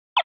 rotate.mp3